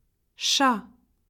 En cliquant sur le symbole, vous entendrez le nom de la lettre.
lettre-ch.ogg